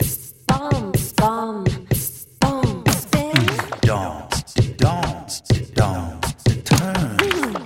DONG Download